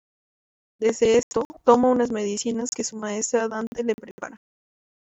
u‧nas
/ˈunas/